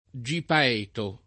vai all'elenco alfabetico delle voci ingrandisci il carattere 100% rimpicciolisci il carattere stampa invia tramite posta elettronica codividi su Facebook gipaeto [ J ip # eto o J ipa $ to ] o gipeto [ J ip $ to ] s. m. (zool.)